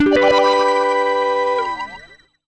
GUITARFX 3-L.wav